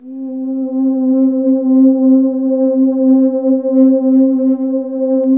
Index of /90_sSampleCDs/Sound & Vision - Gigapack I CD 2 (Roland)/PAD_SYNTH-PADS 2/PAD_Synth-Pads 5
PAD      0AR.wav